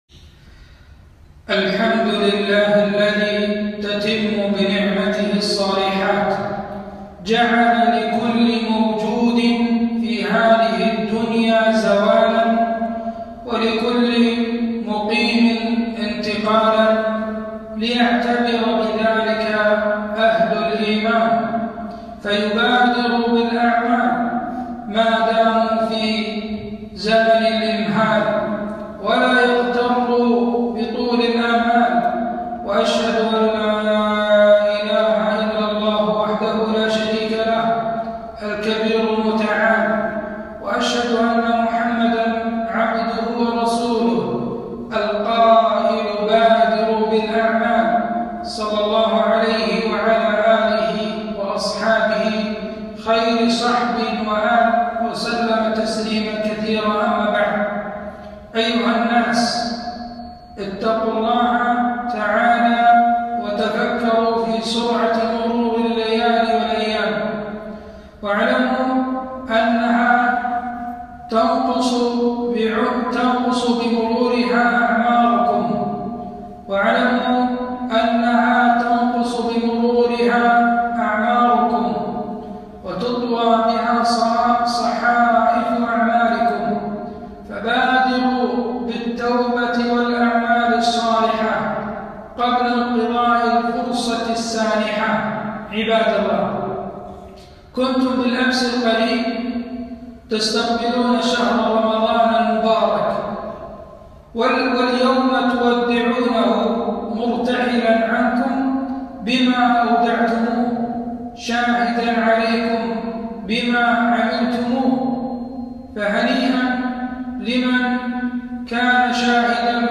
خطبة - أعمال مشروعة في ختام رمضان